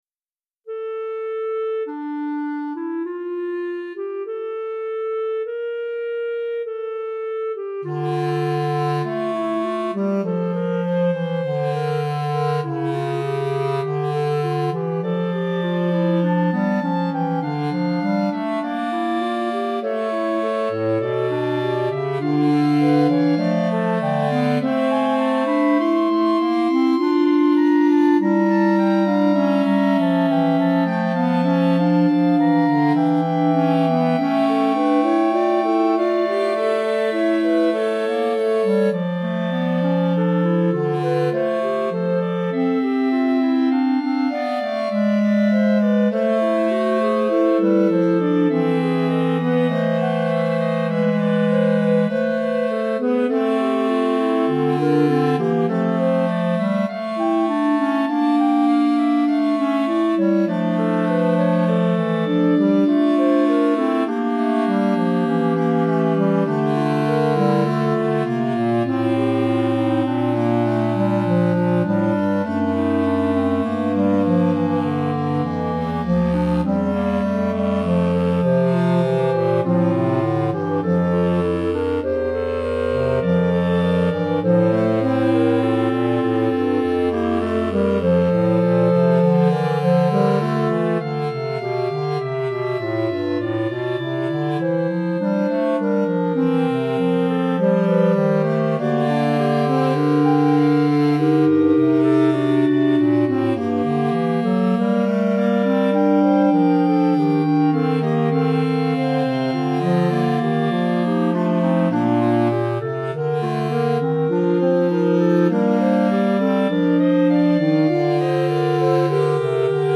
Répertoire pour Clarinette - 4 Clarinettes